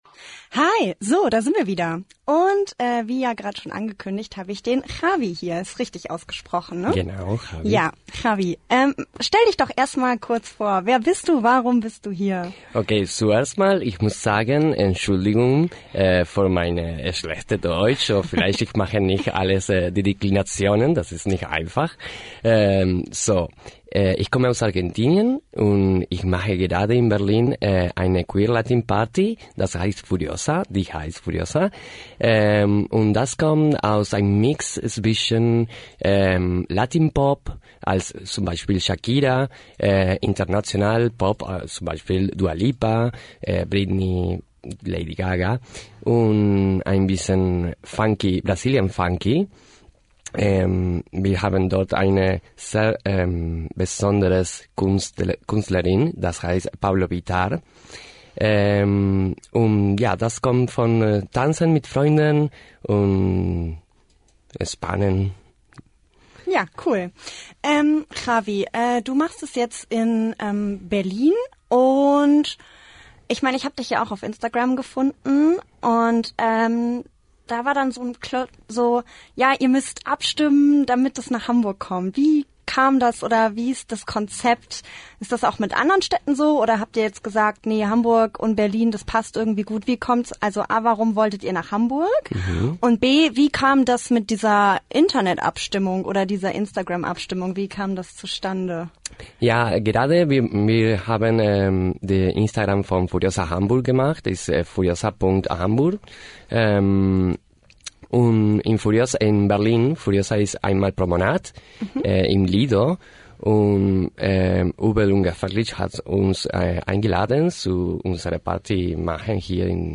– Das ganze Interview.